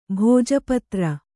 ♪ bhōja patra